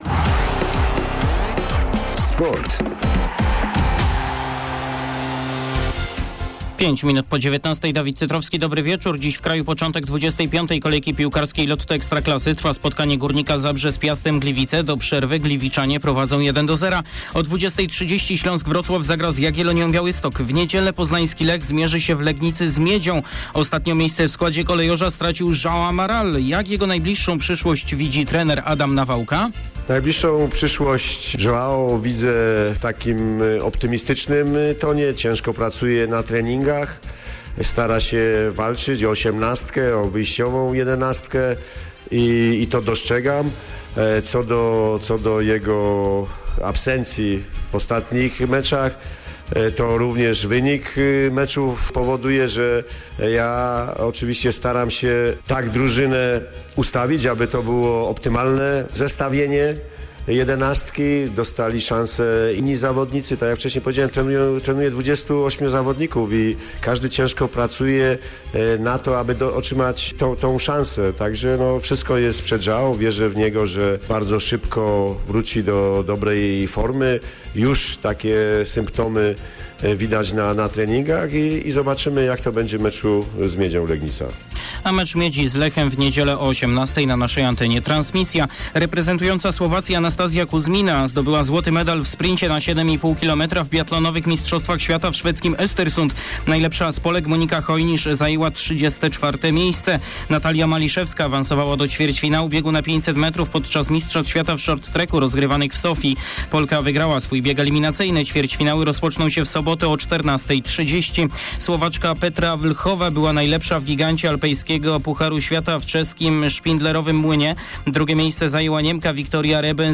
08.03. serwis sportowy godz. 19:05